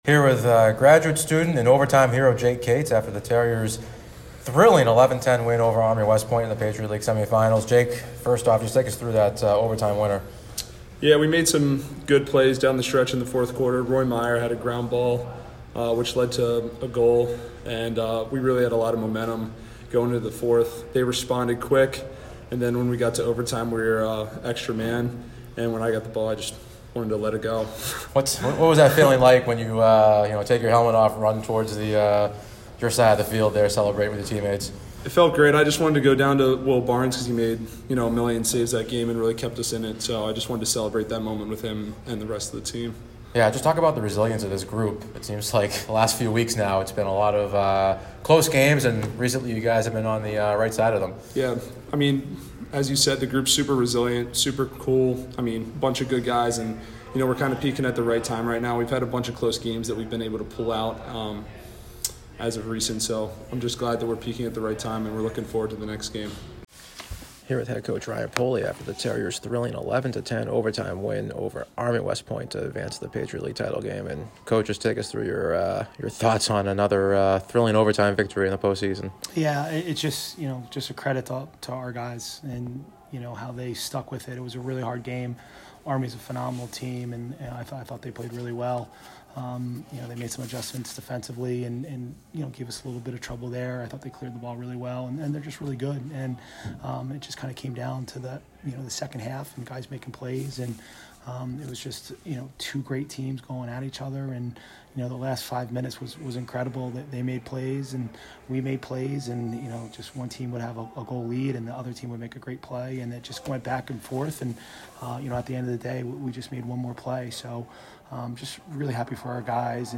Men's Lacrosse / PL Semifinal Postgame Interview